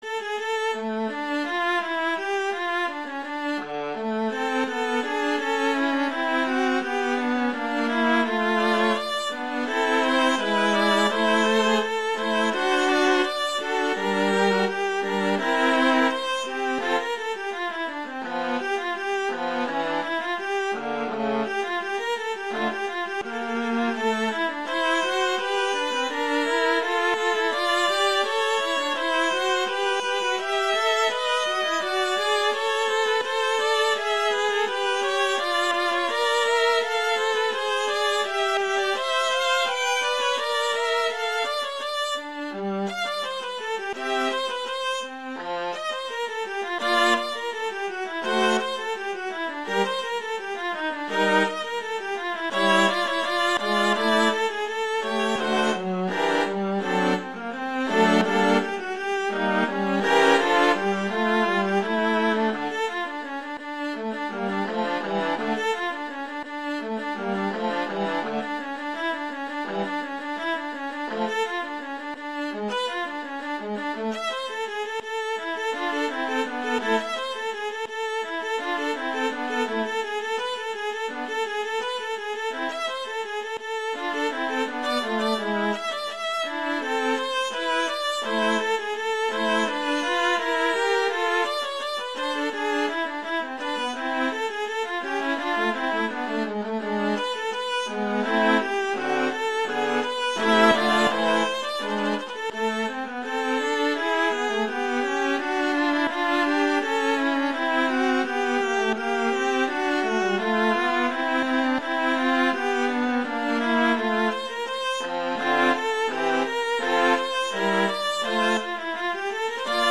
viola solo
classical
F major